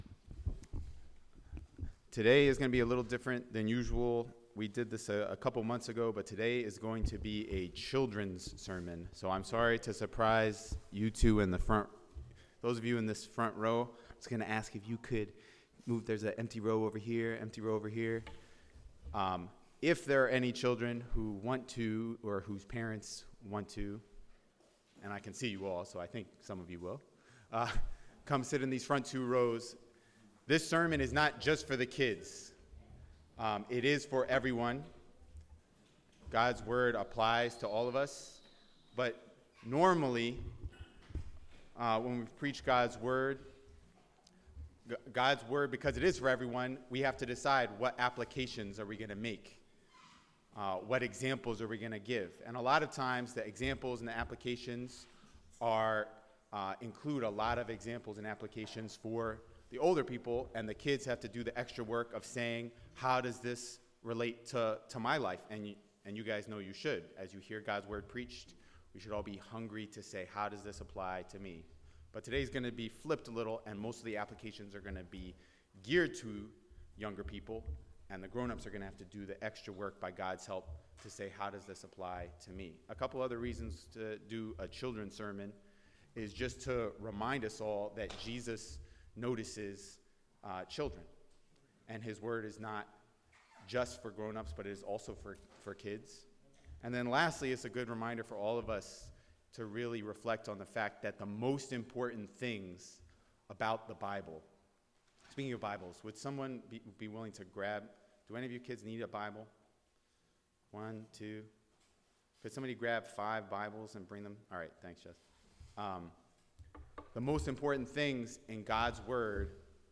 Children’s Sermon: Jesus vs. The Bad Things
Passage: Luke 8:22-25 Service Type: Sunday Morning